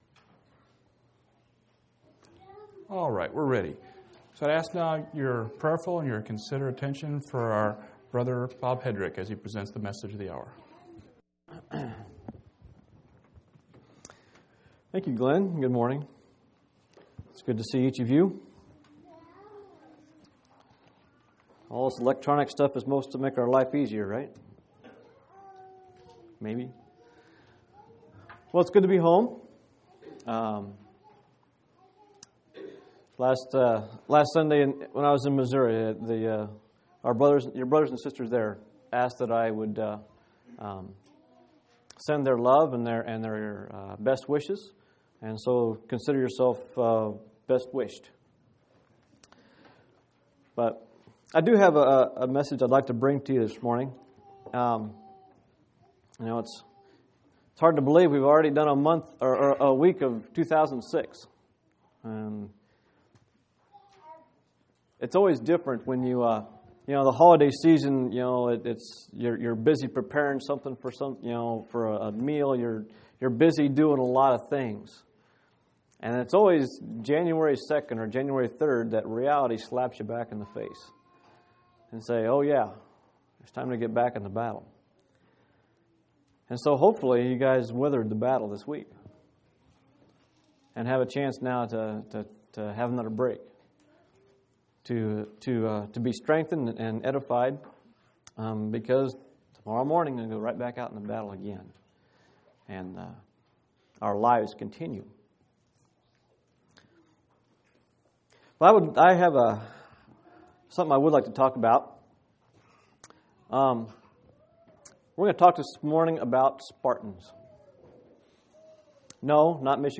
1/8/2006 Location: Phoenix Local Event